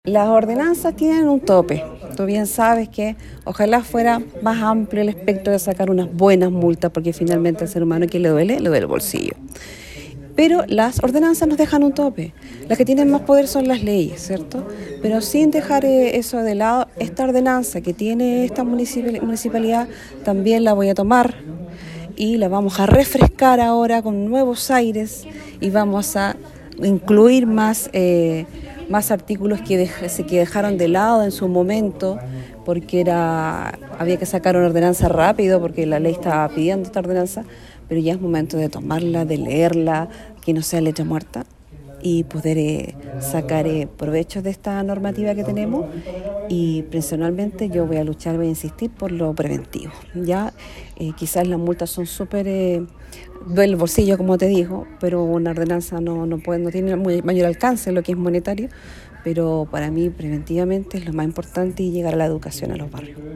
La Concejala Canales destacó la importancia de reformar la ordenanza municipal, con los aspectos que la ley les permite como la ejecución de multas, pero abogó principalmente a la educación ambiental que se necesita en la comuna para terminar con el maltrato animal.